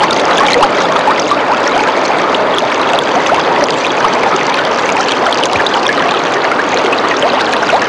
Another Babbling Brook Sound Effect
Download a high-quality another babbling brook sound effect.
another-babbling-brook.mp3